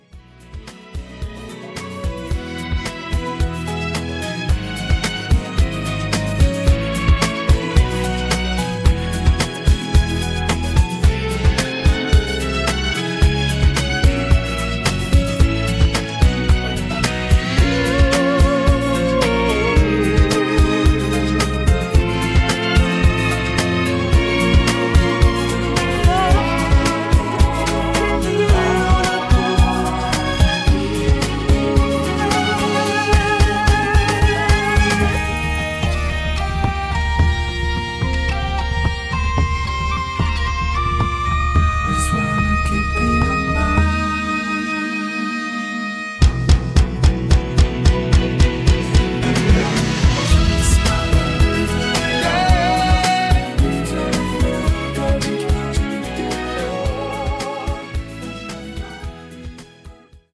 음정 -1키 4:57
장르 가요 구분 Voice MR